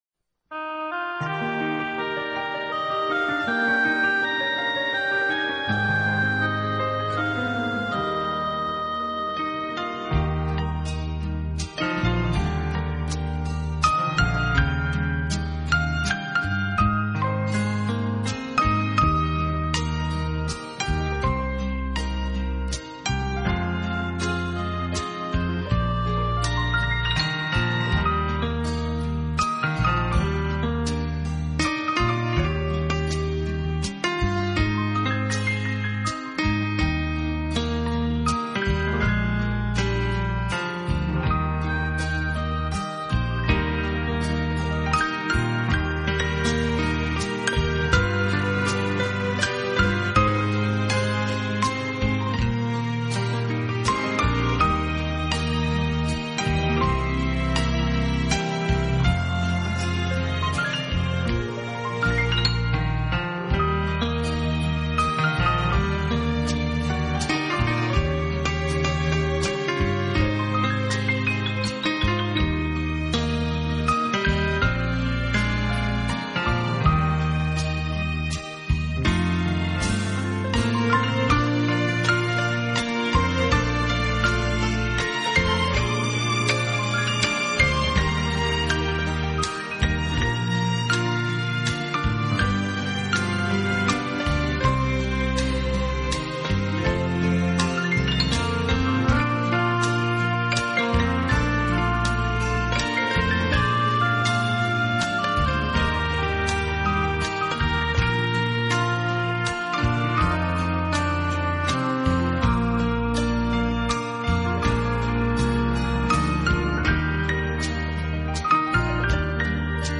音乐类型：Instrumental
整张专辑全部都是以浪漫情调为主题的钢琴合辑，当熟